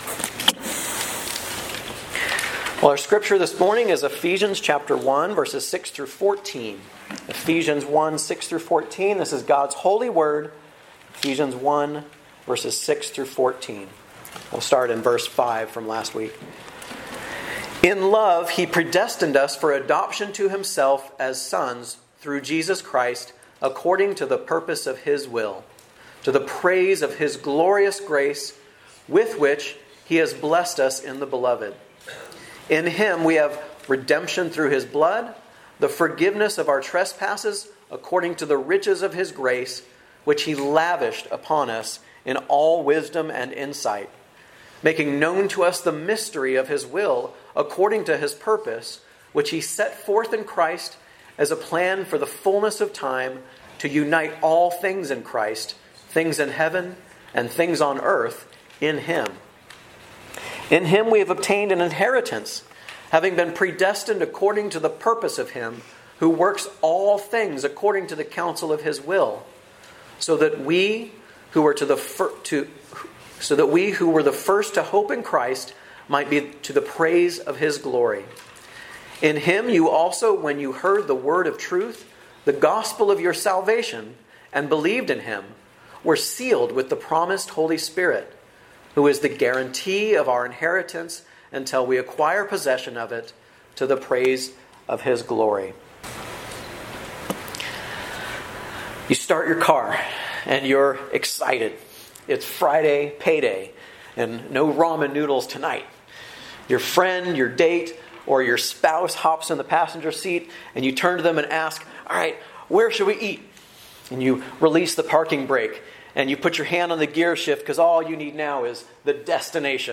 From Series: "Guest Preacher"